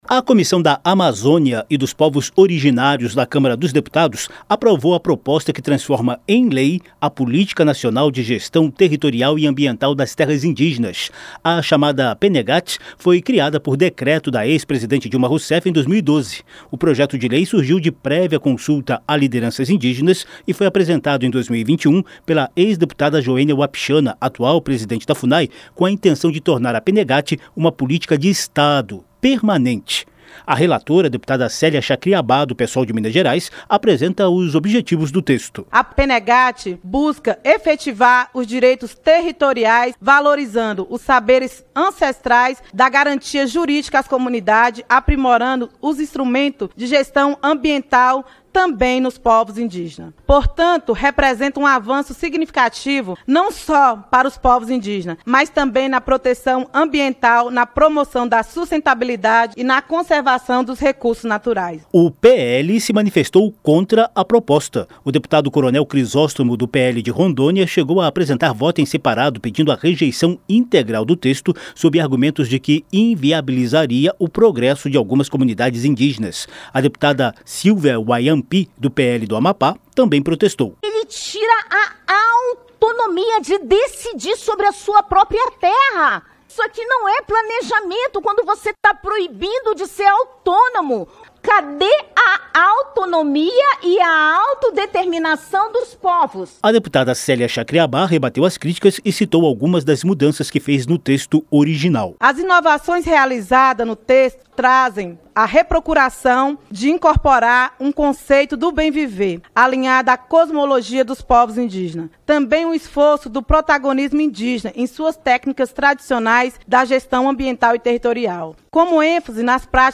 Comissão aprova Política Nacional de Gestão para Terras Indígenas - Radioagência - Portal da Câmara dos Deputados